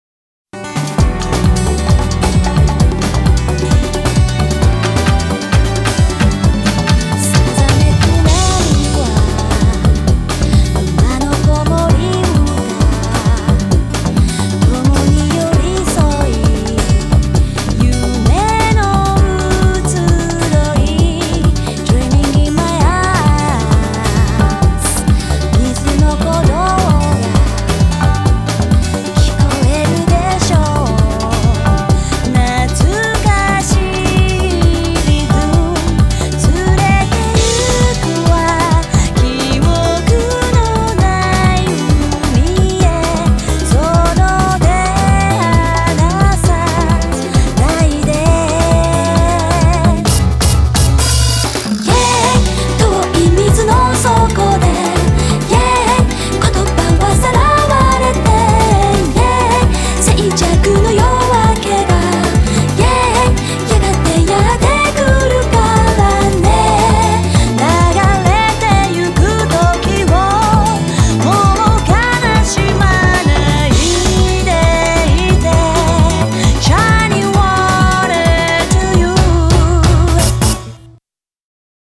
BPM132
Audio QualityPerfect (High Quality)
Genre: JAZZ/HOUSE.